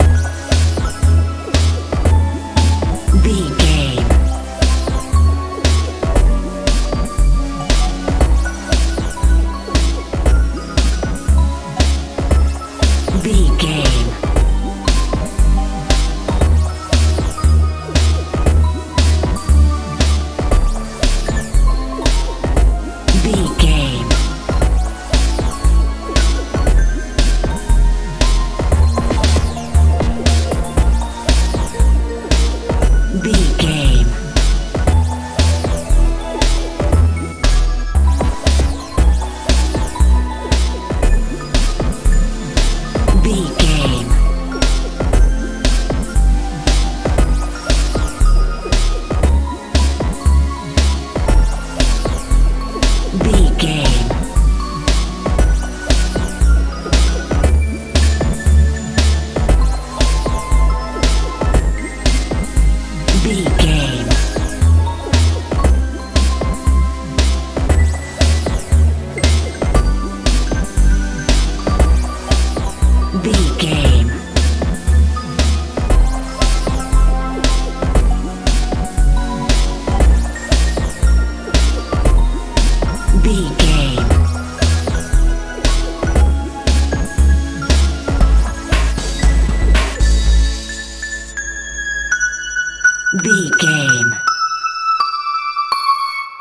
techno
Ionian/Major
magical
mystical
synthesiser
bass guitar
drums
strange
suspense
tension